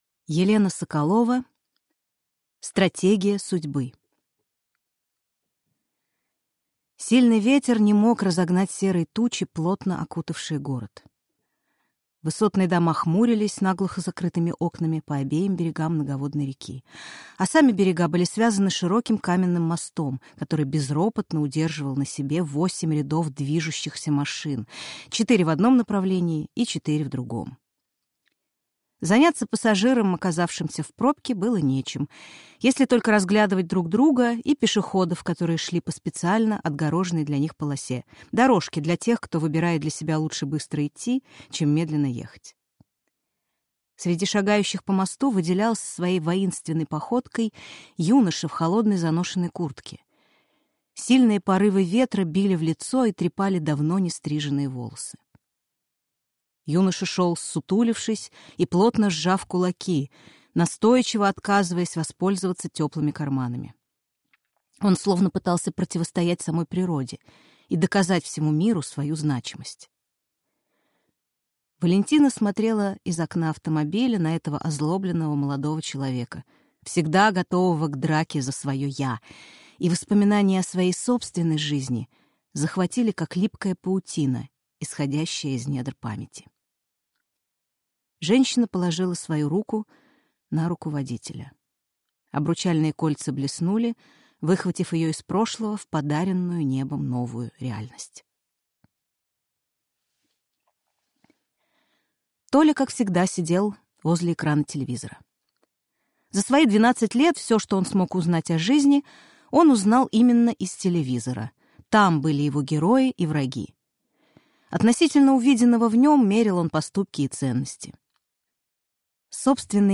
Аудиокнига Стратегия судьбы | Библиотека аудиокниг